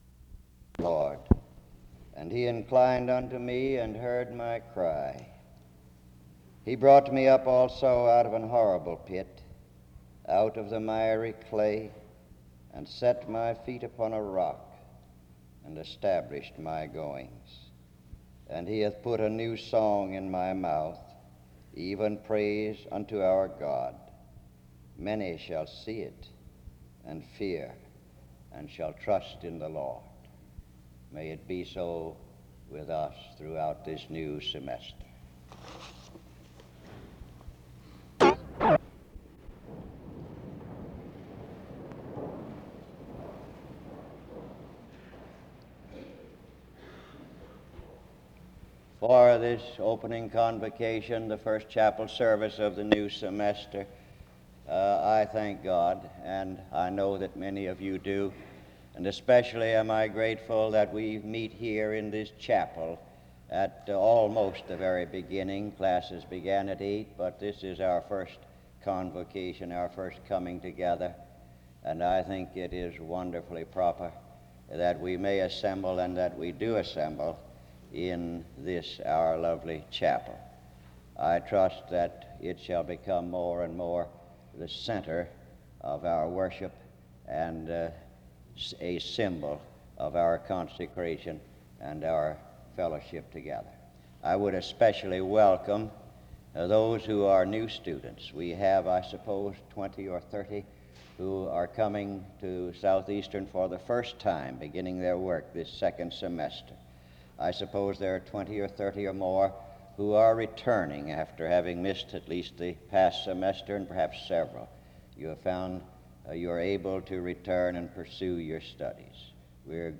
The service begins with the reading of Psalm 40:2-3 (00:00-00:47). A general word of welcome (00:48-02:41) precedes an opening prayer (02:42-05:19).
Pastoral counseling